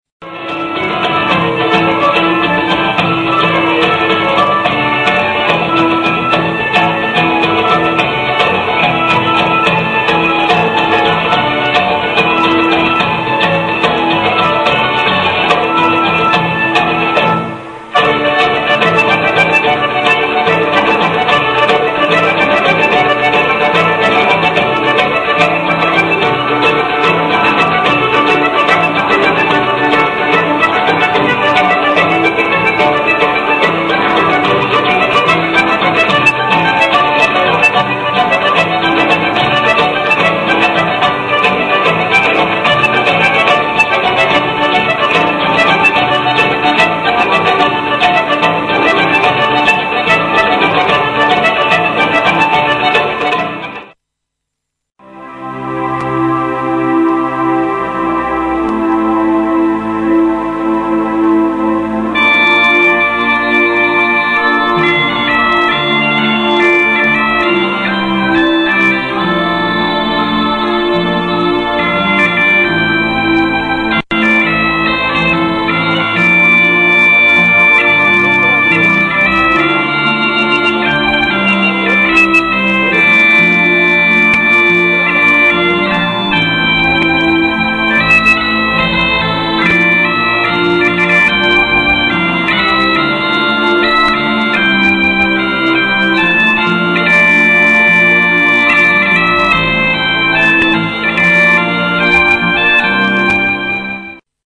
in concerto presso Palazzo Uboldi
registrato con la fotocamera